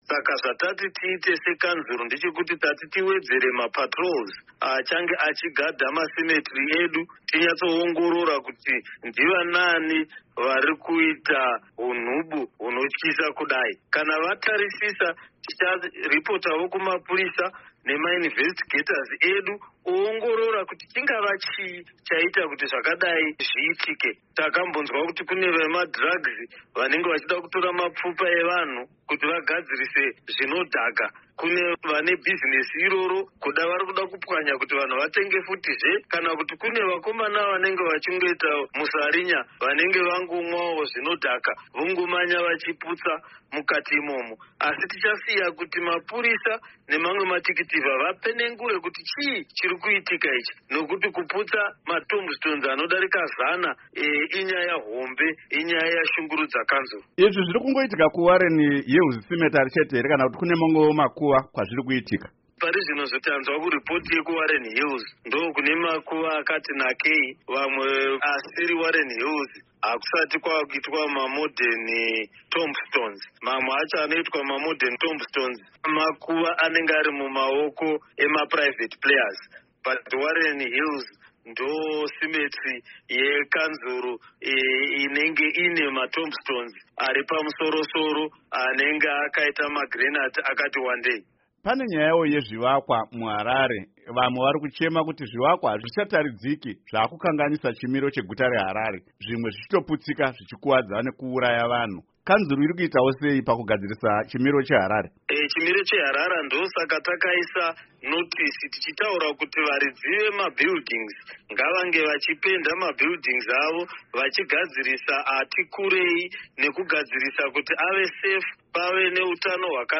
Hurukuro naMeya veHarare VaJacob Mafume